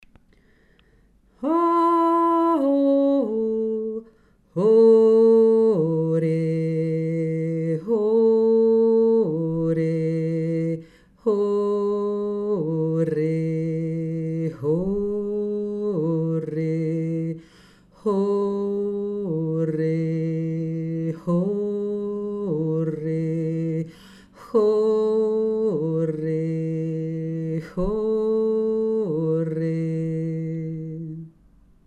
2. Stimme